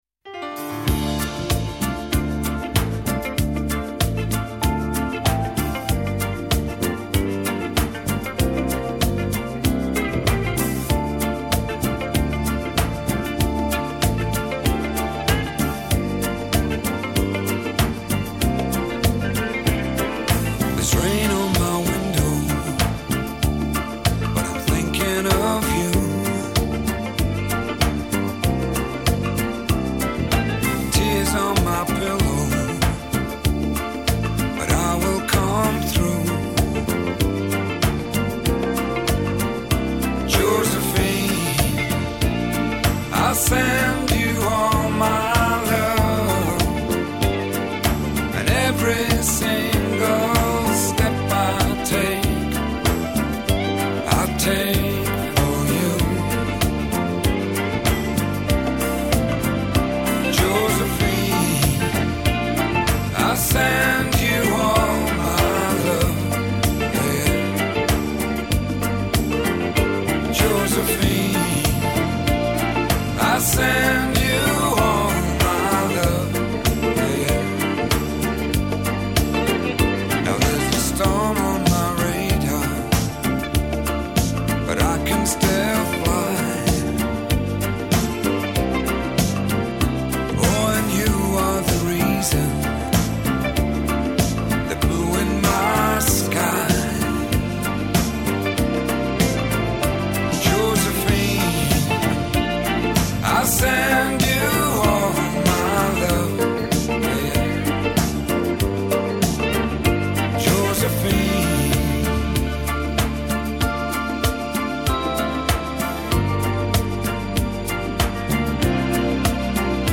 Жанр: foreignbard